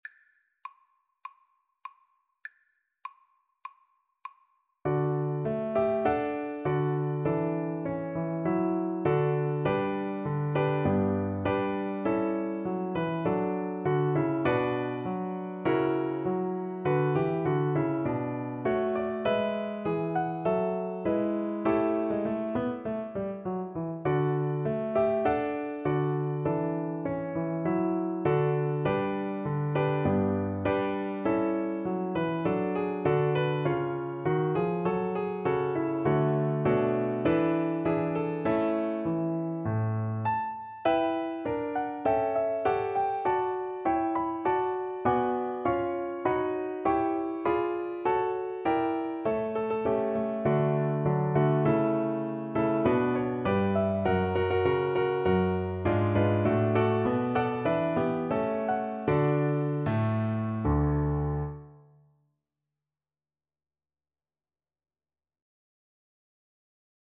4/4 (View more 4/4 Music)
D major (Sounding Pitch) (View more D major Music for Viola )
irish_nat_anth_VLA_kar1.mp3